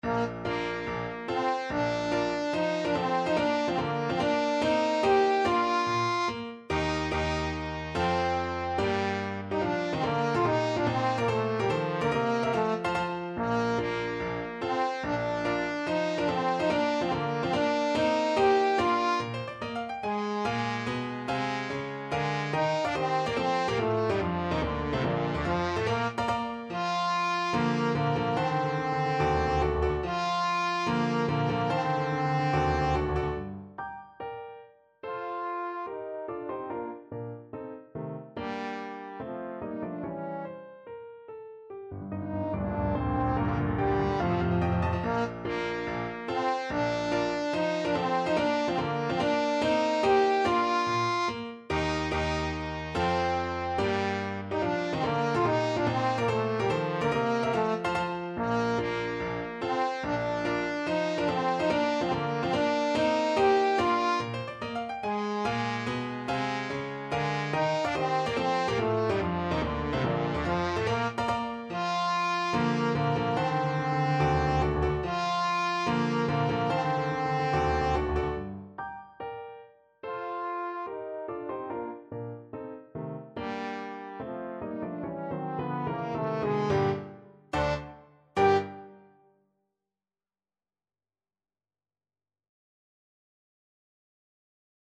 4/4 (View more 4/4 Music)
Allegro non troppo (=72) (View more music marked Allegro)
Bb3-G5
Classical (View more Classical Trombone Music)